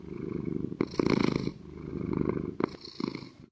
purr3.ogg